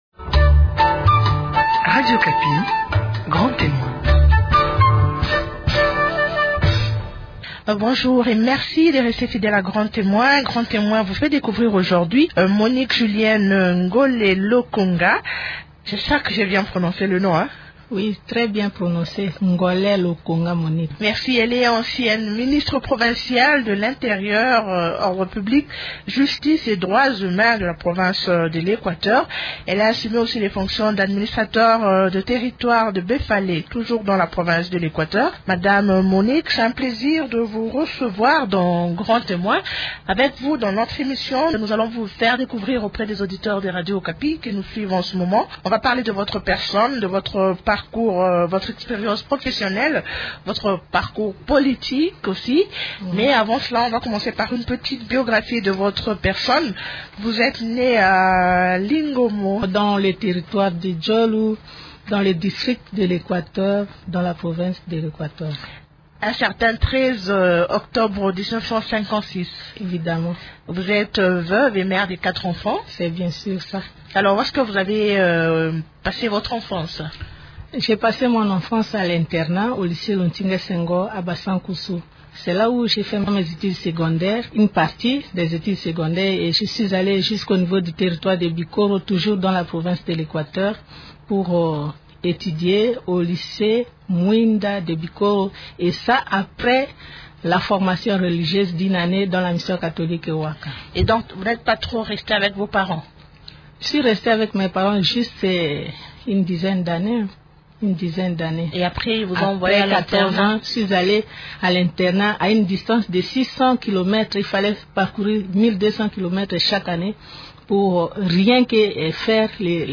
Dans son entretien